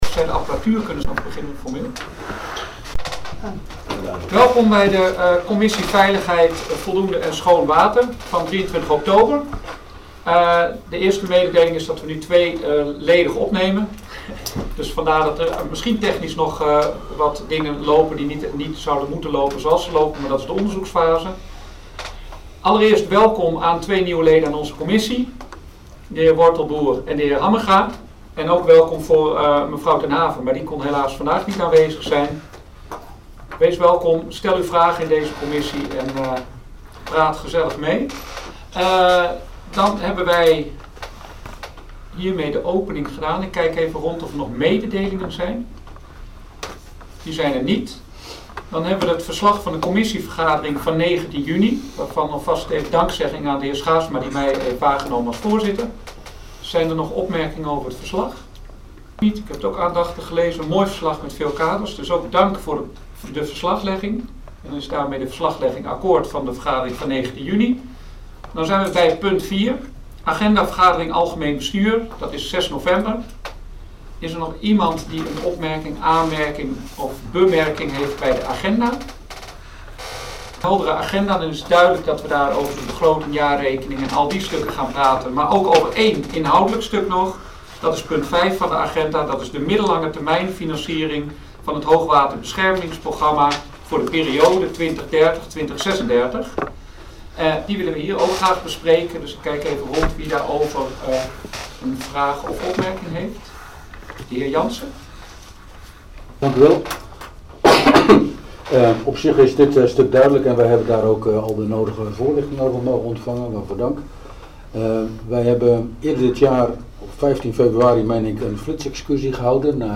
Commissievergaderingen 23 oktober 2024
audio opname commissie VVSW 23 oktober 2024